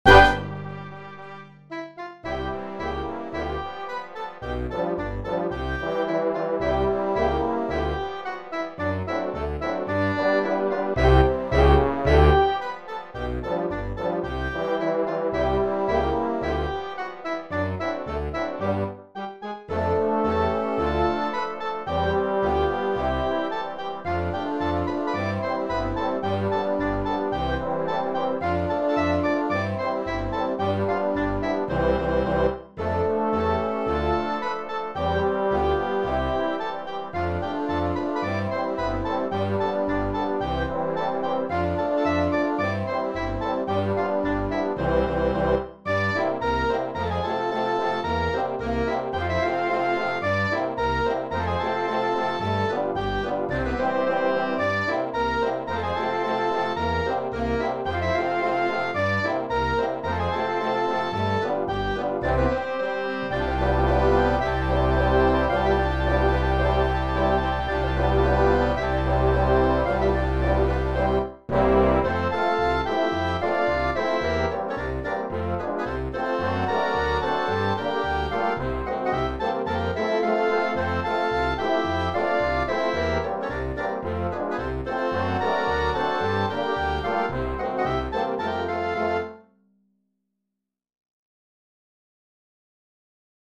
für großes Blasorchester…
Blasmusik , Polkas & Boarische